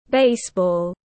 Môn bóng chày tiếng anh gọi là baseball, phiên âm tiếng anh đọc là /ˈbeɪsbɔːl/ .
Baseball /ˈbeɪsbɔːl/